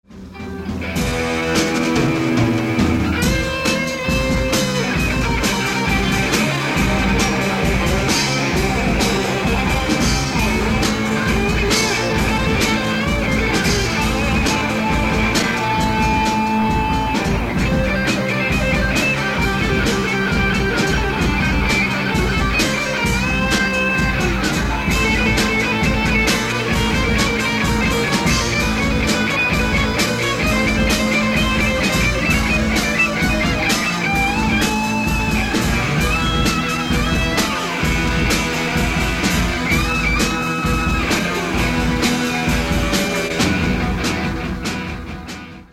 Ukazky su nahravana skuska , stero kazetak Tesla Diamant v strede miestnosti, nemali sme spevaka.
solo z rychlejsej skladby:
Aj ked falosne je to teda dost, ako som to po rokoch pocuval No hanbím sa..